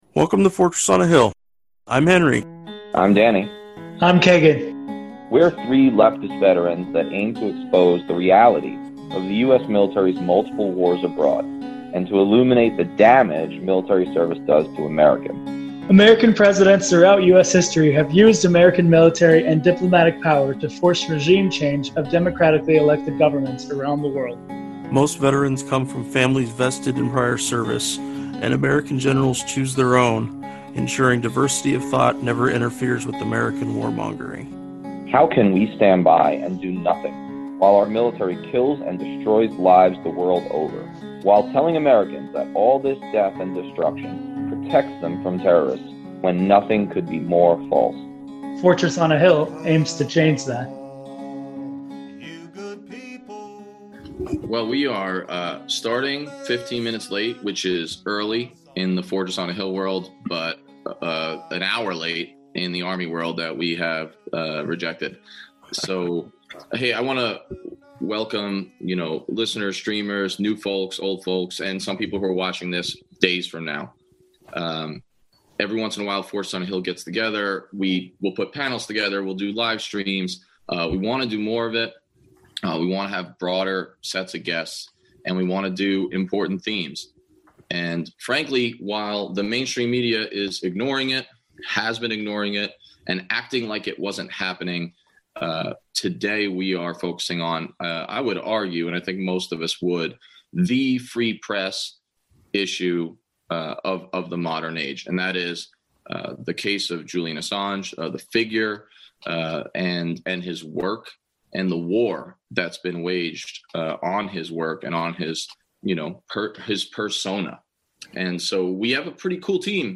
Assange verdict live stream (audio only) – Ep 90